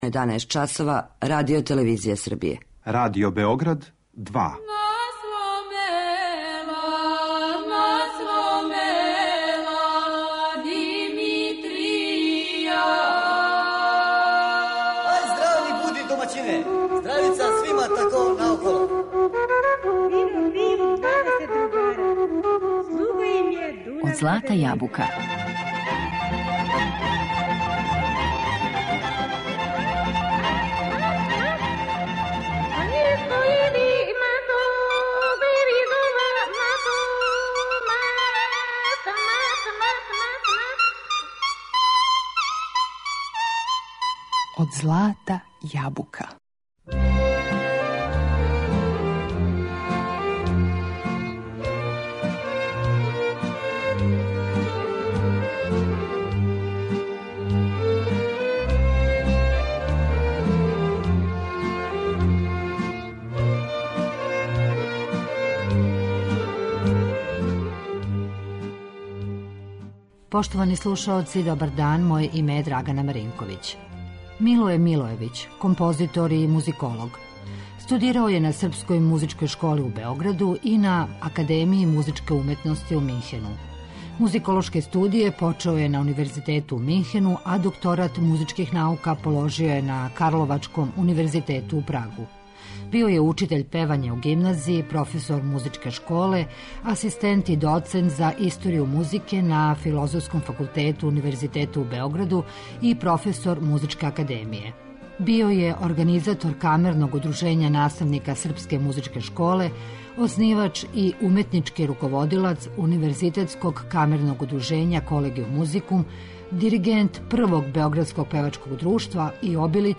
Данас говоримо и слушамо примере записа градских народних игара и песама са простора Косова и Метохије, које је нотирао и записао Милоје Милојевић.